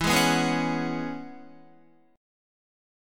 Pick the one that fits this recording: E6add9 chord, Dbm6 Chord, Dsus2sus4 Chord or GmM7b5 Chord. E6add9 chord